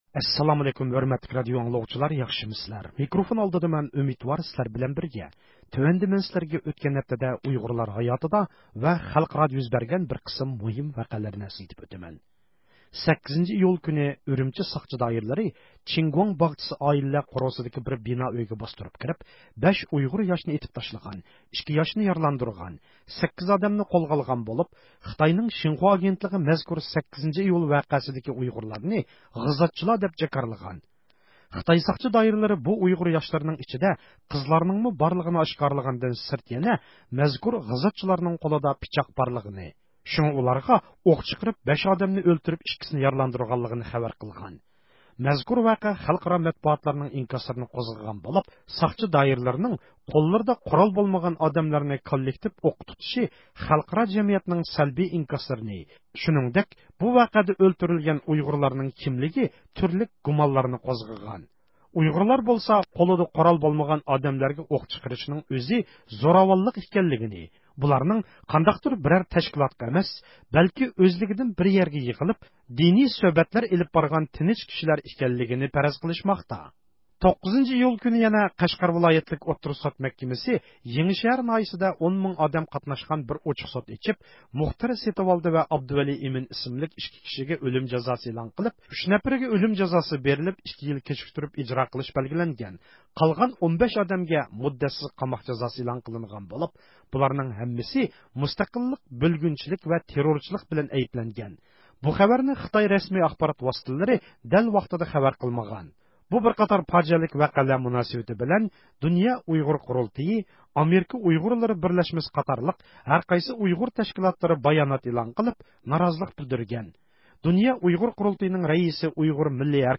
ھەپتىلىك خەۋەرلەر (5 – ئىيۇلدىن 11 – ئىيۇلغىچە) – ئۇيغۇر مىللى ھەركىتى